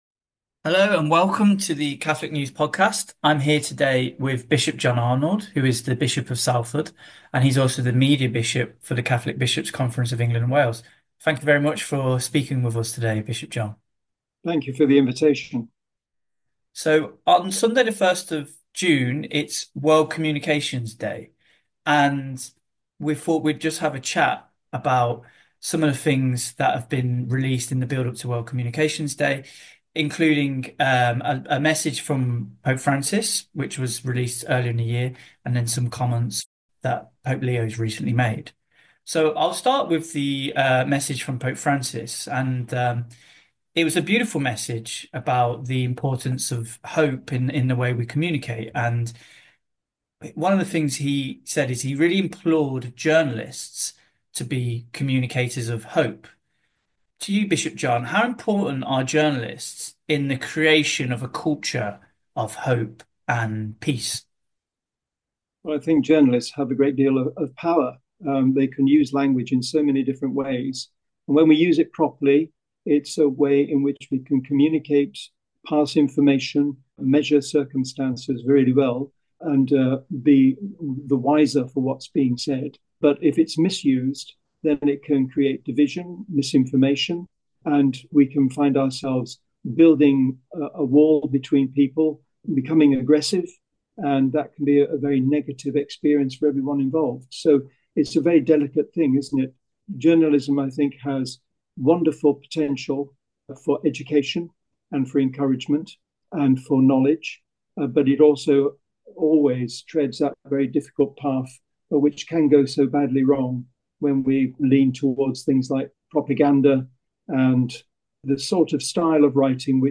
The theme centres around the need to communicate responsibly to foster a culture of hope and peace. For this Catholic News podcast, we speak to our Media Bishop, the Rt Revd John Arnold, to discuss the final Communica...